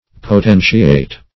Potentiate \Po*ten"ti*ate\, v. t. [imp. & p. p. Potentiated;